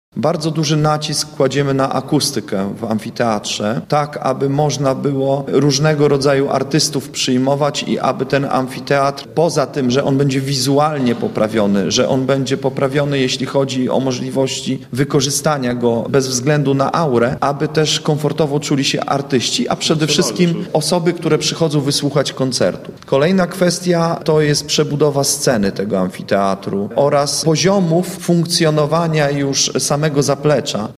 – Ważne jest także akustyka, przebudowa sceny oraz zaplecze – mówi zastępca prezydenta miasta Adam Chodziński